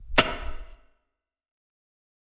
pickaxe_2.wav